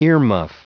Prononciation du mot earmuff en anglais (fichier audio)
Prononciation du mot : earmuff